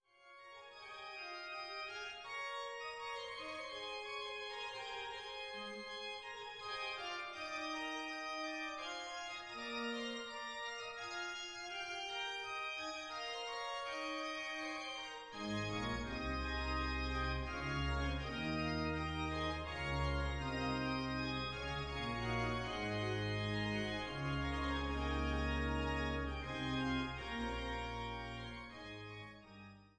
Orgel in Forchheim, Pfaffroda, Nassau und Ponitz